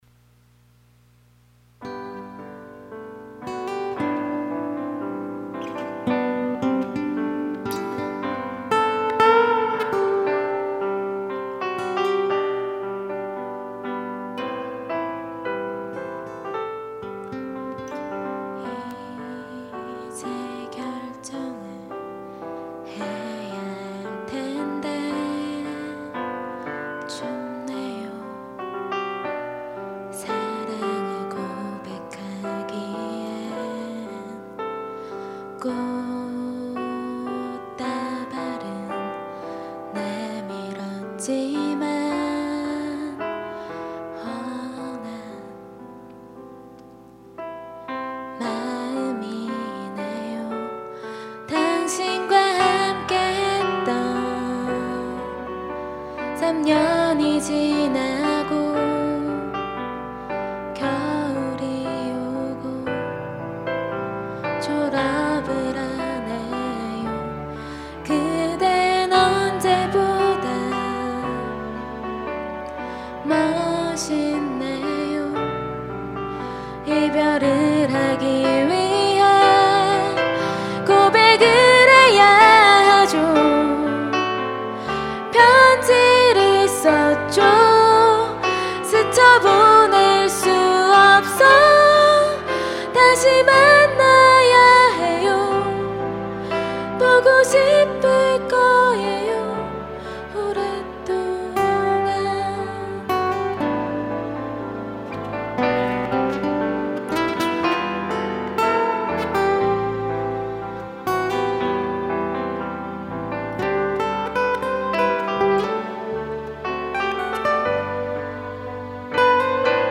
2005년 신입생 환영공연
홍익대학교 신축강당
어쿠스틱기타
신디사이저